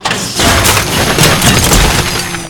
recycle.ogg